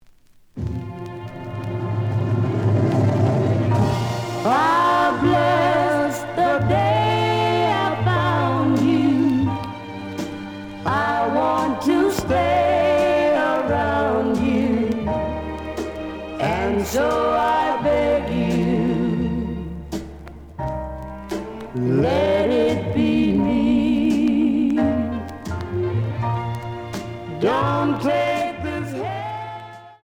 試聴は実際のレコードから録音しています。
●Genre: Rhythm And Blues / Rock 'n' Roll
●Record Grading: VG~VG+